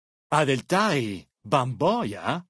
Dead Horses pidgin audio samples Du kannst diese Datei nicht überschreiben.